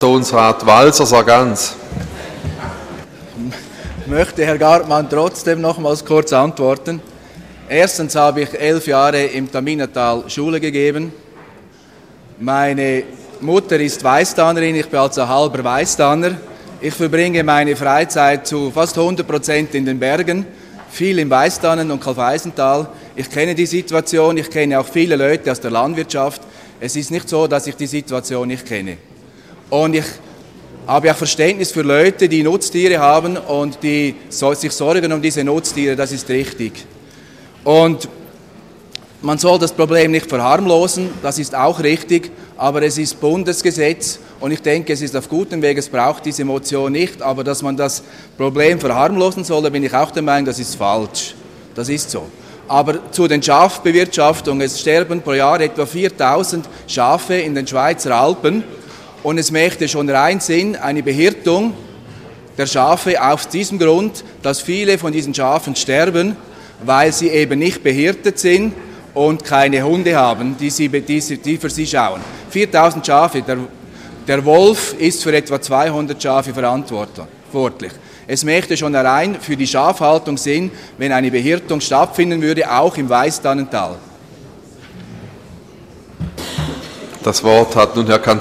Session des Kantonsrates vom 23. bis 25. Februar 2015